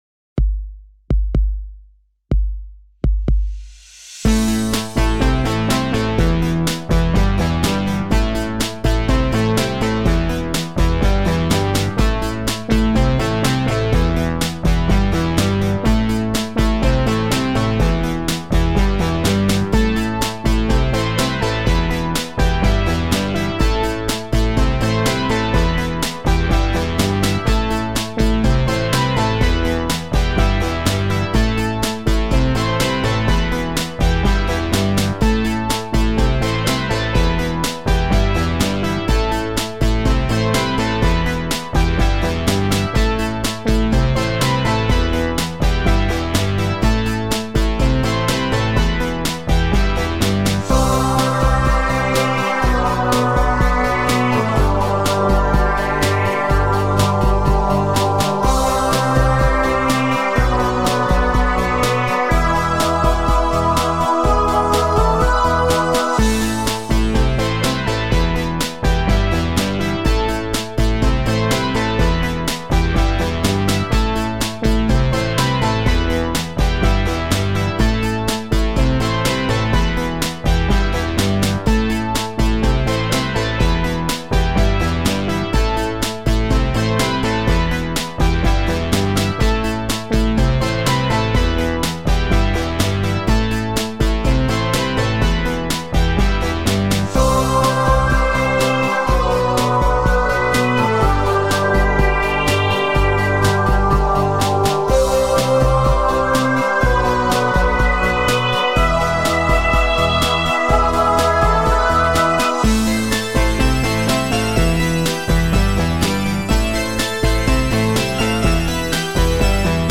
Übungsaufnahmen - Das Model
Das Model (Playback)